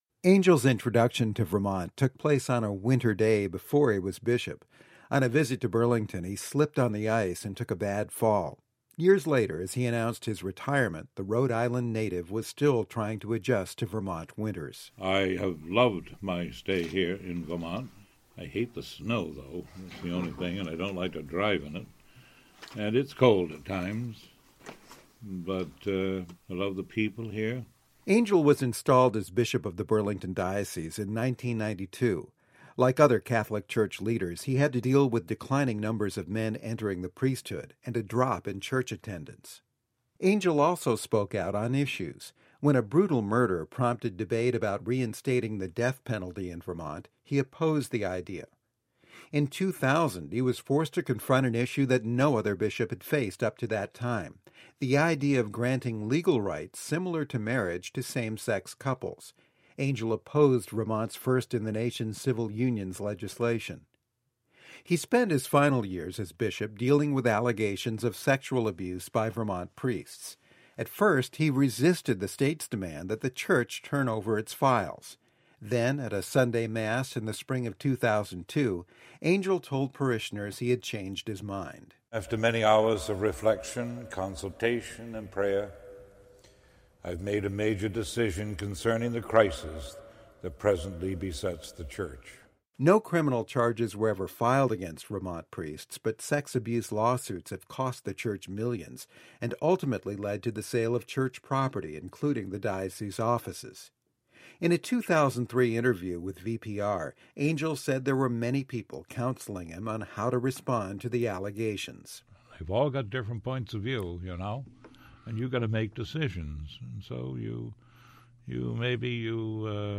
In a 2003 interview with Vermont Public Radio, Angell said there were many people counseling him on how to respond to the sexual abuse allegations.
Angell was a large man, and when leading a Mass, wearing the vestments of his office and speaking in his booming voice, he cut an imposing and remote figure.